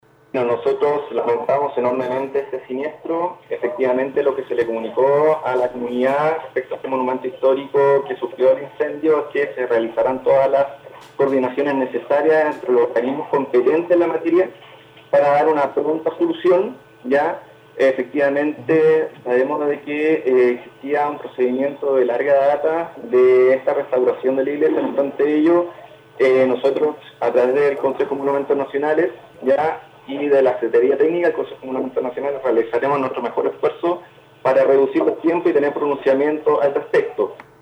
Los profesionales levantarán un informe técnico, a la espera del pronunciamiento de Bomberos y otros organismos con respecto a las causas del incendio, indicó Felipe León.